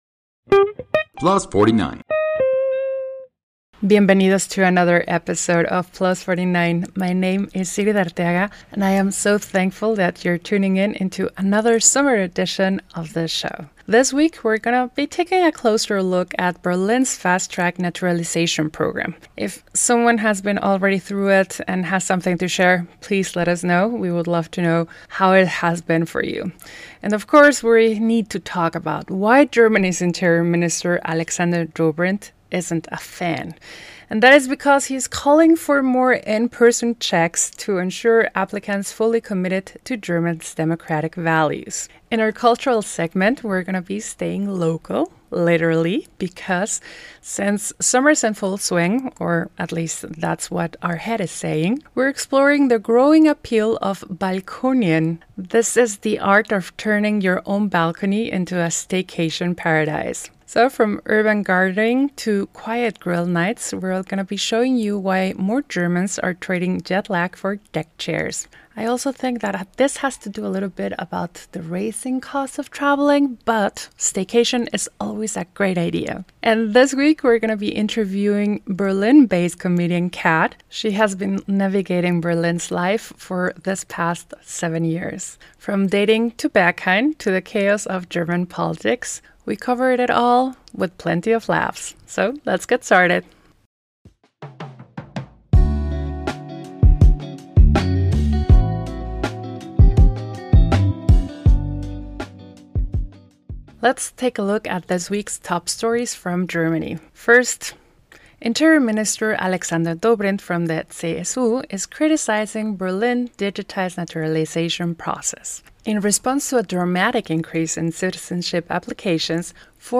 And in our interview